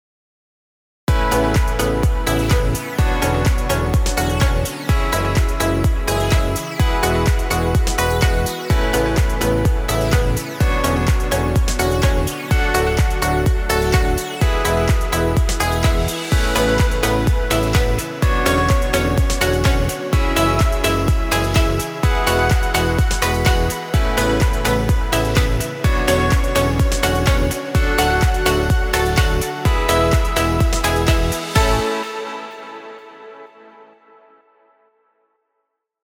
dance track with nice beats.
Background Music Royalty Free.